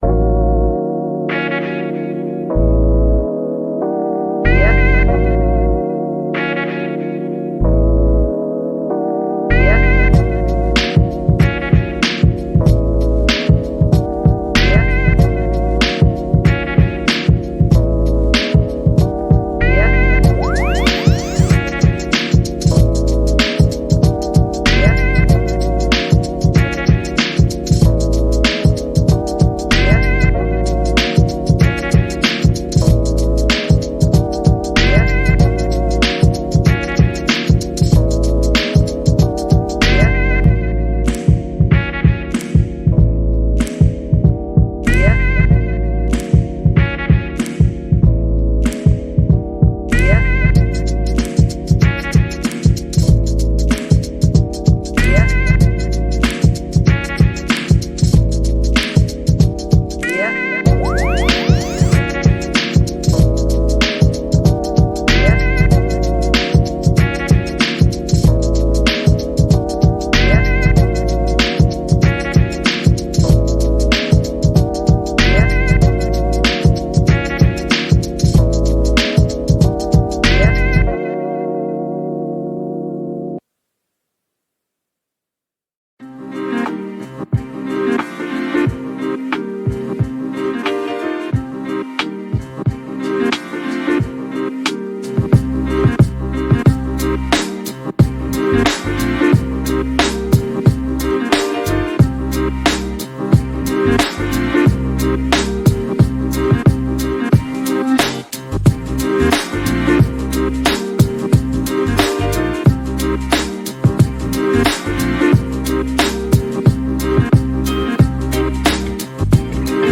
KGbr7Tbm0nS_5-minute-lo-fi-hiphop-beats-study-timer.mp3